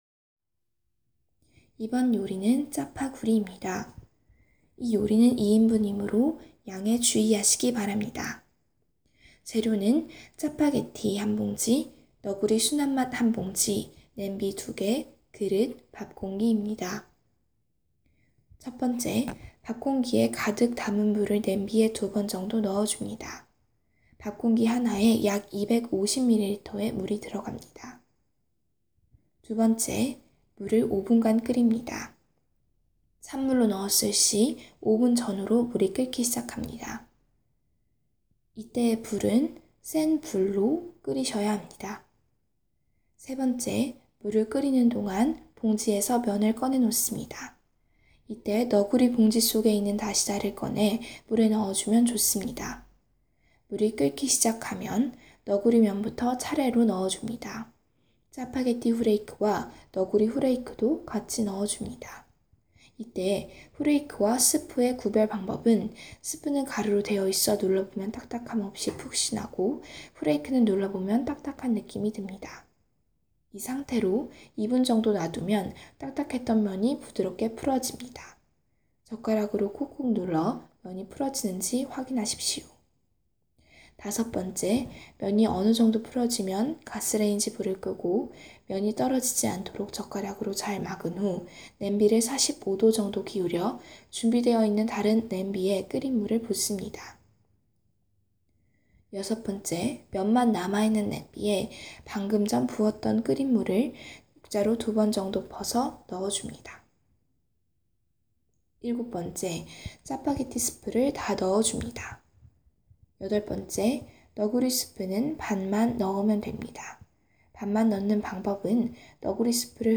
밑에 첨부파일은 저희가 활동하고있는 페이스북 현황과, 직접 만든 짜파구리 레시피 음성파일입니다.